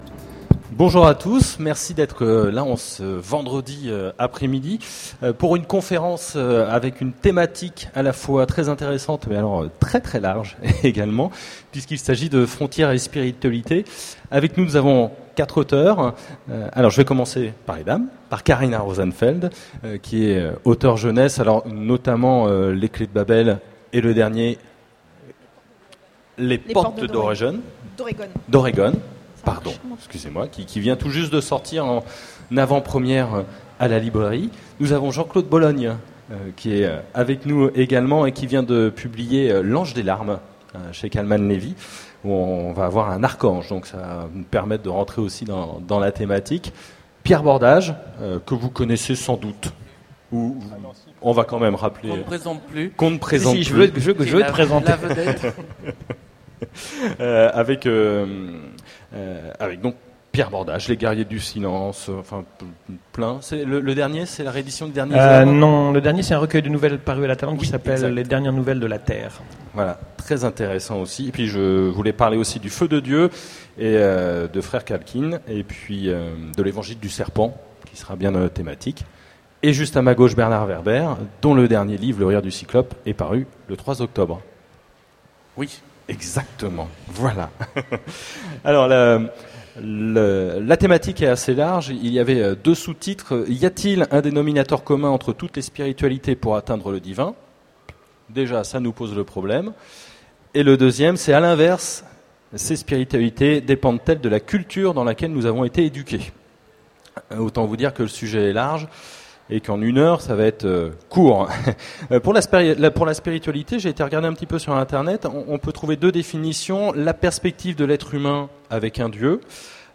Utopiales 2010 : Conférence Frontières et Spiritualité
Voici l'enregistrement de la conférence " Frontières et Spiritualité " aux Utopiales 2010. Y-a-t-il un dénominateur commun entre toutes les spiritualités pour atteindre le divin ? À l’inverse, dépendent-elles de la culture dans laquelle nous avons été éduqués ?